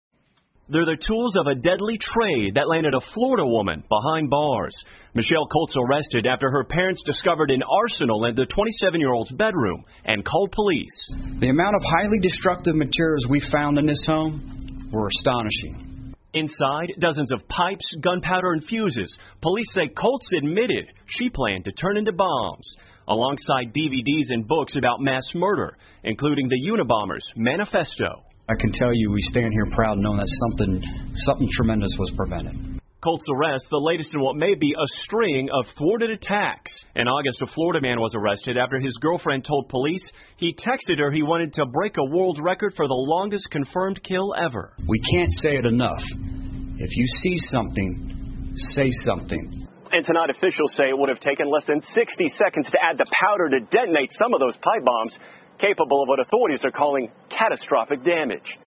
NBC晚间新闻 女子房间藏炸药被捕 听力文件下载—在线英语听力室